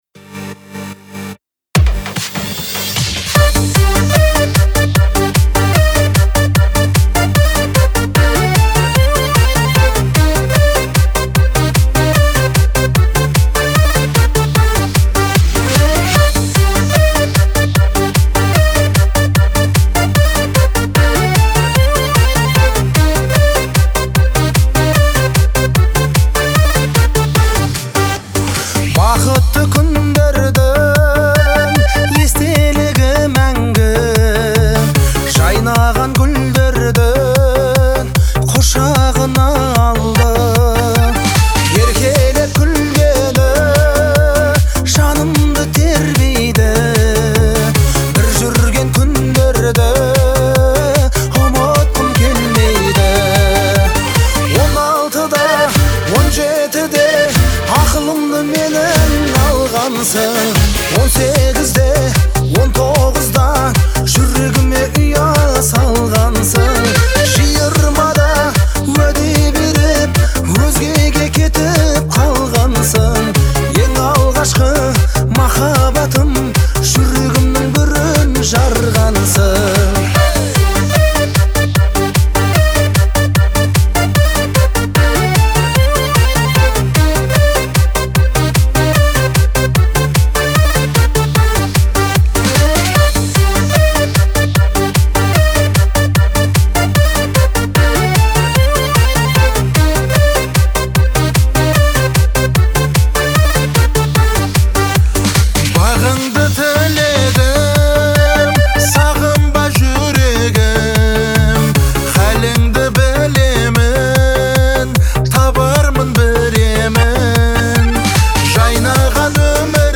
это трек в жанре поп, наполненный меланхолией и ностальгией.